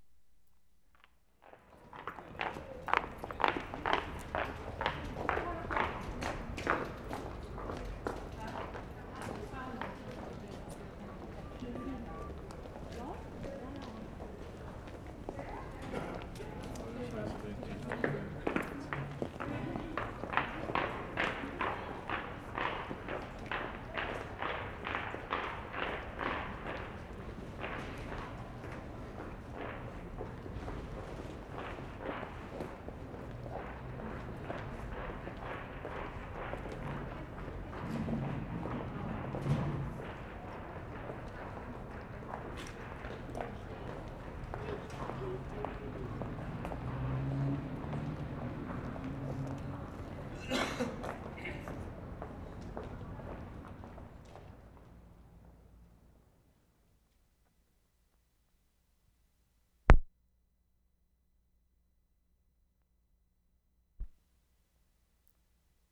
Stockholm, Sweden Feb. 9/75
CLOGS ON PAVEMENT
3&4. Girls walking across a street (no traffic).